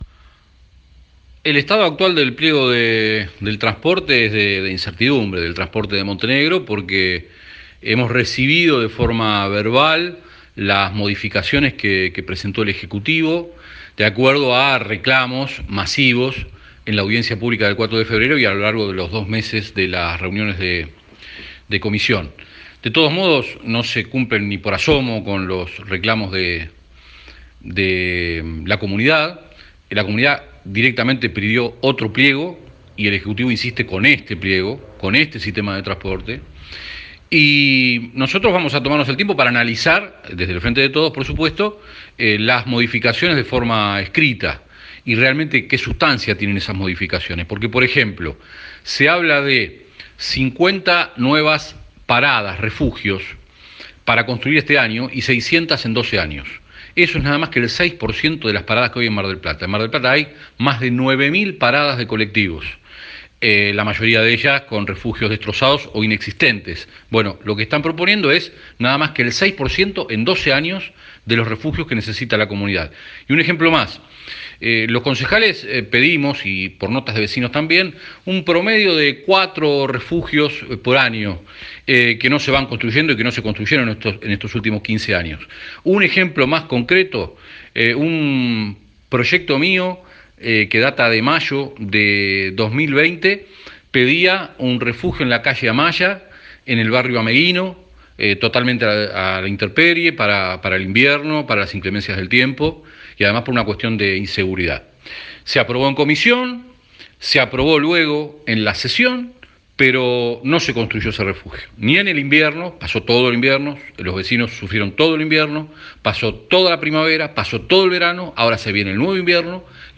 Vito Amalfitano, concejal del Frente de Todos, conversó con MdpYa sobre el estado actual del pliego de transporte: “Estamos ante una incertidumbre porque hemos recibido de forma verbal las modificaciones que presentó el Ejecutivo de acuerdo a reclamos masivos en la audiencia pública del 4 de febrero y a lo largo de los dos meses de las reuniones de comisión, y no se cumplen ni por asomo los reclamos de la comunidad que pidió otro pliego, pero el Ejecutivo insiste.”